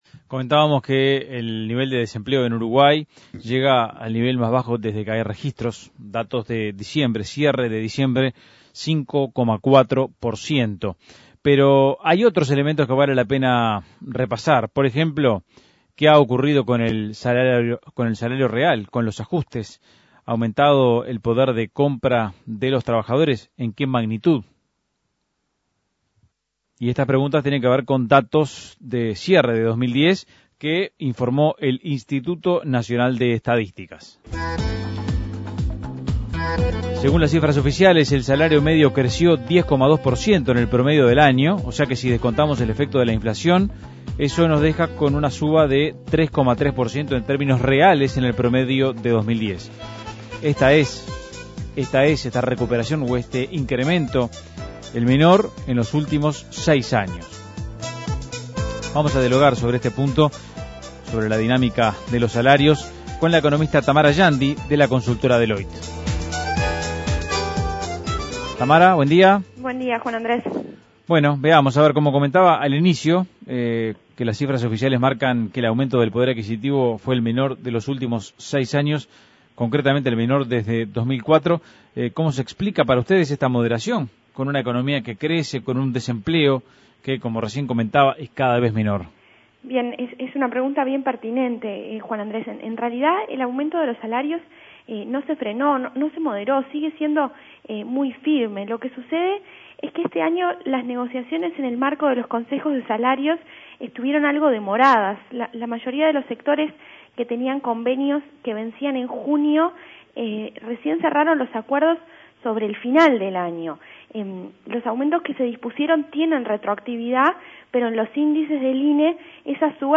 Análisis Económico ¿Cuánto crece el salario real?